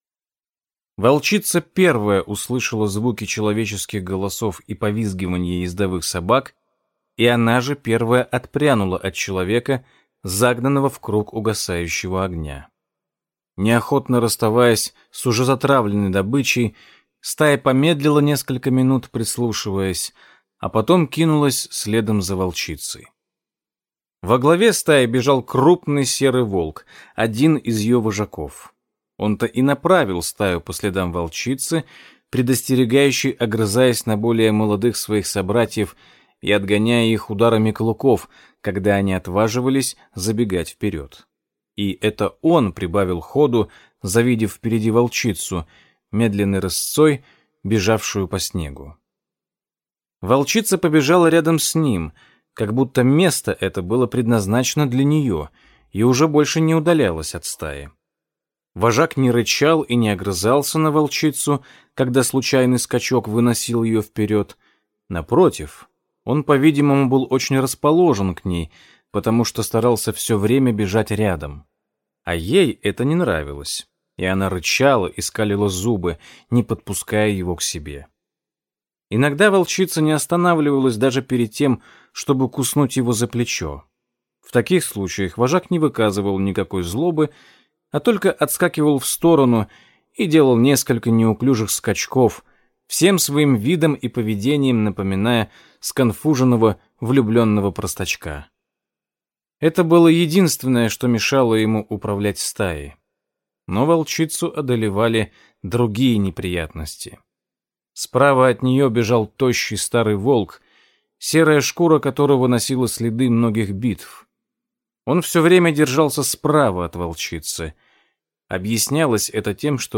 Аудиокнига Белый Клык. Зов предков | Библиотека аудиокниг